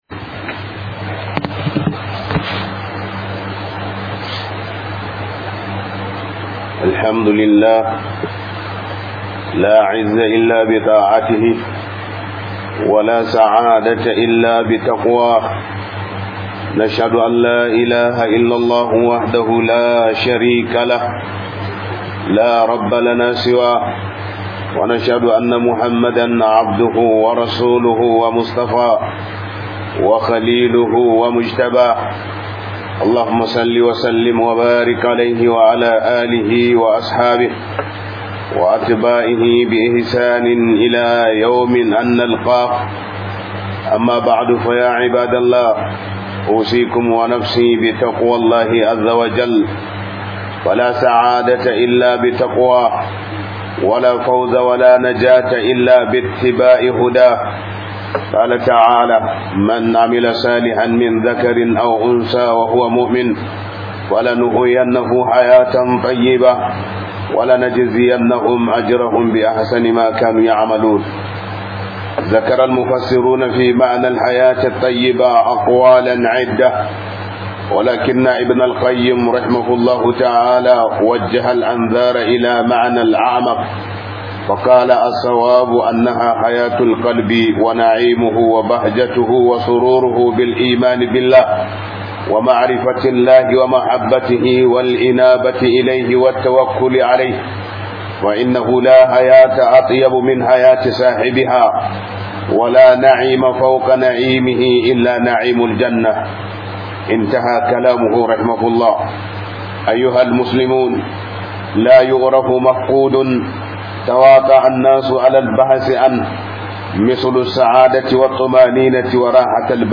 Arzikin Rayuwa - HUDUBA